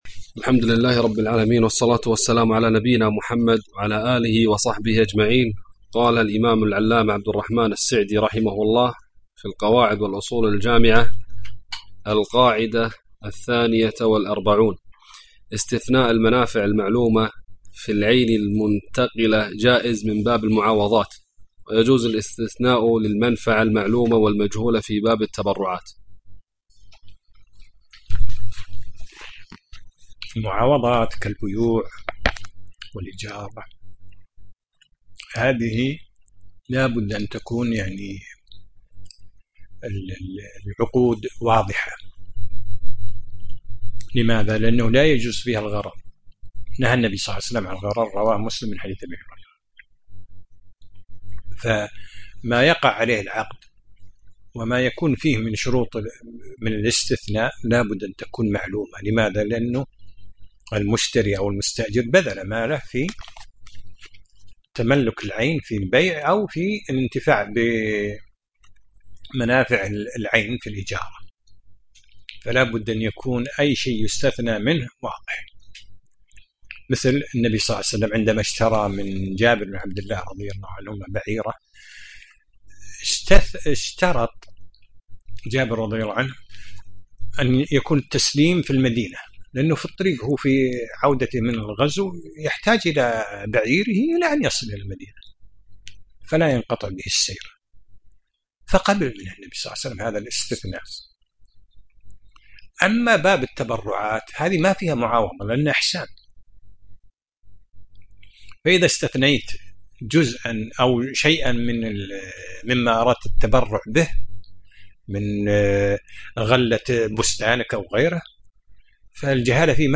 الدرس الثالث عشر : من القاعدة 42 إلى القاعدة 44